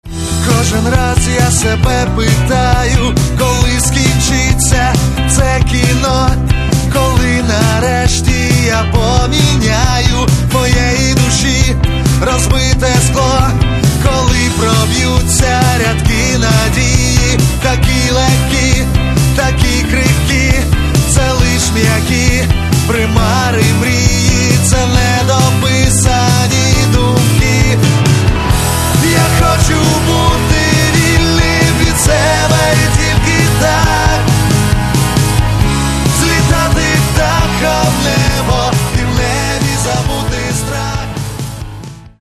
Каталог -> Рок и альтернатива -> Просто рок